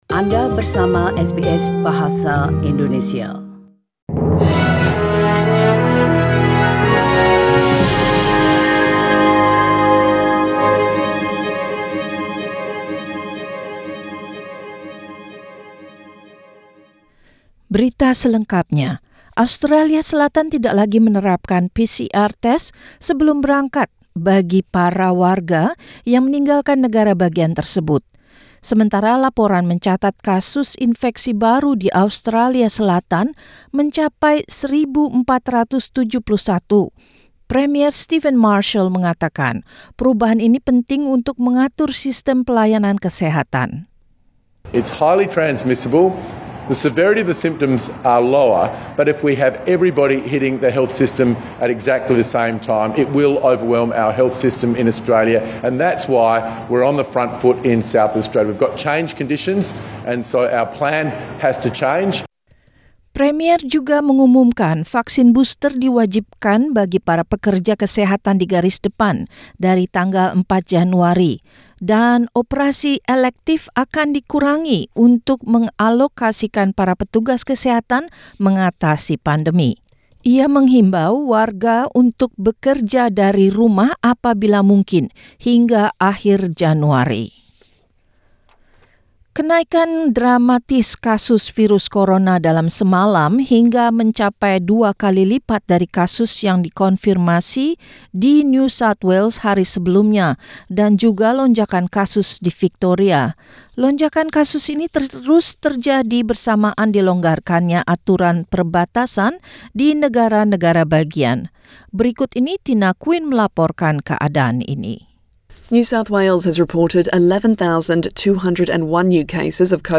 SBS Radio News in Indonesian - Wednesday, 29 December 2021
Warta Berita Radio SBS Program Bahasa Indonesia.